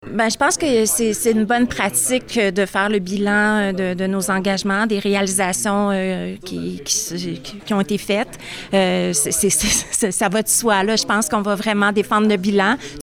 Elle a indiqué, lundi, lors de la séance du conseil, qu’elle ne changerait pas ses plans de campagne et défendrait son bilan.